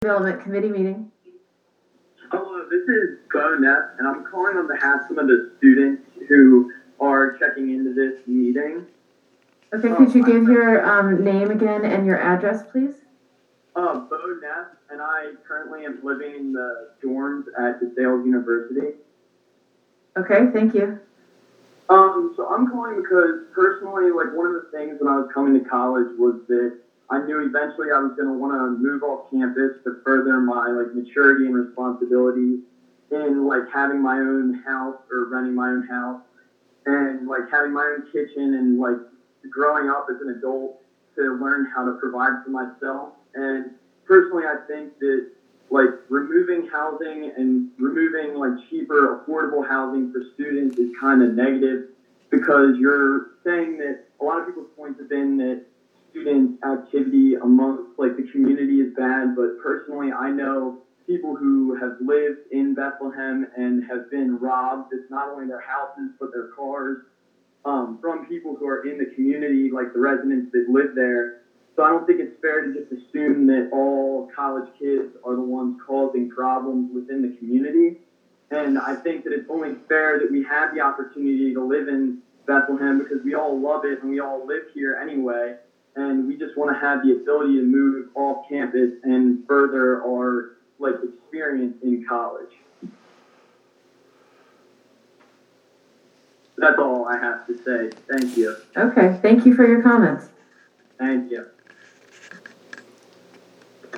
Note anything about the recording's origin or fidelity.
About 20 residents commented at the meeting, the overwhelming majority in favor of the ordinance.